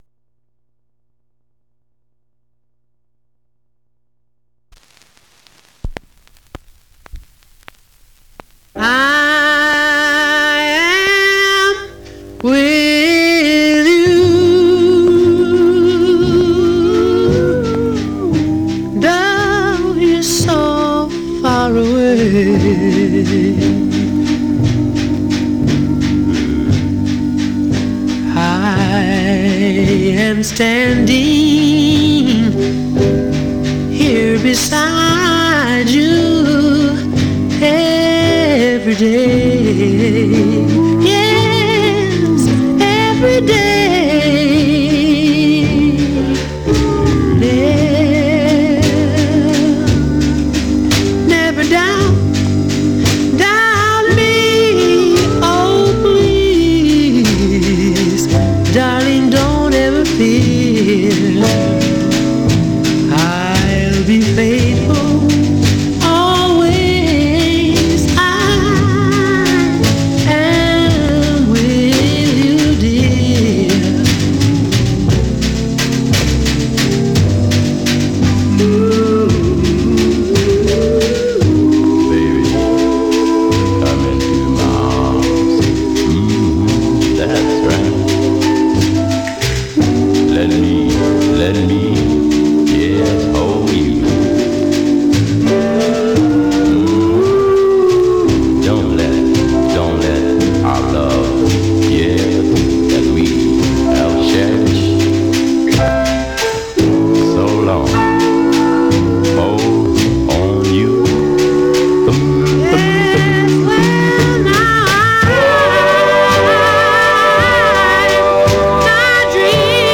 Mono
Some surface noise/wear
Male Black Groups